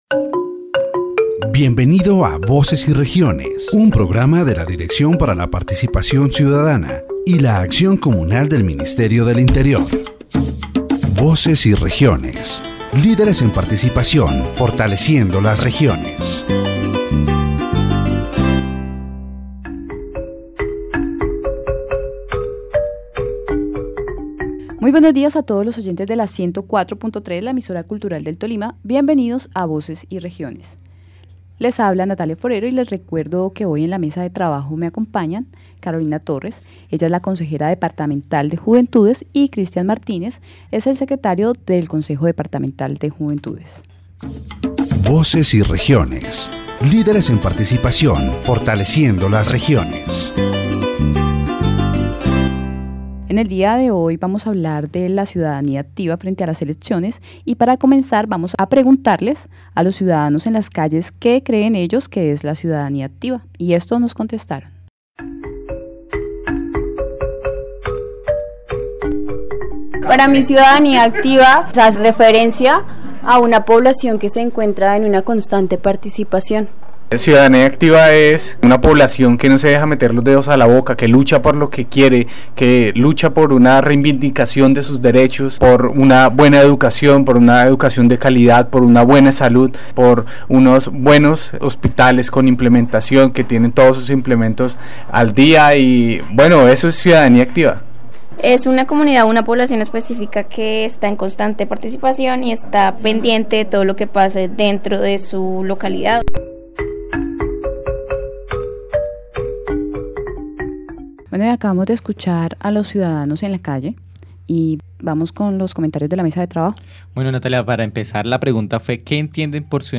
In this edition of Voces y Regiones, the concept of active citizenship is explored through interviews with passersby about their knowledge and perception of the topic. The hosts analyze its importance in democracy and highlight Article 45 of the Colombian Constitution, which recognizes and protects the rights of young people, ensuring their participation in the country's political, social, and economic life.